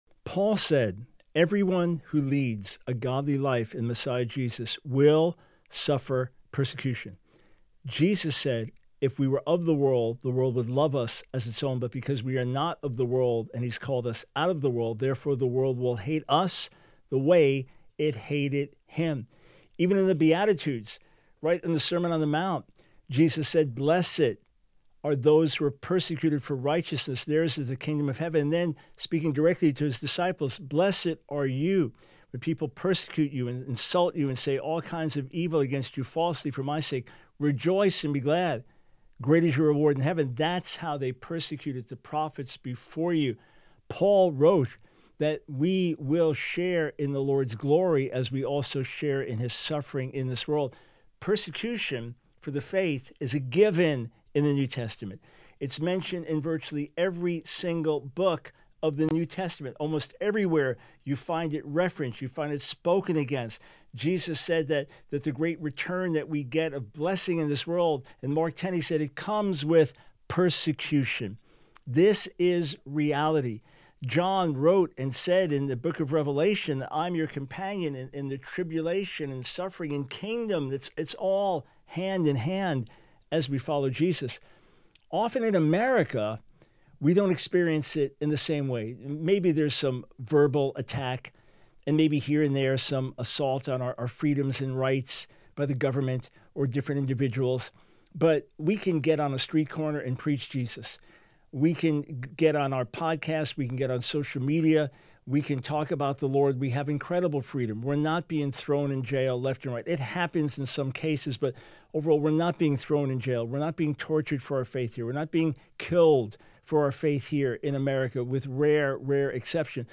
interview
Radio Broadcast